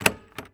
AudioClip_Click-Switch.wav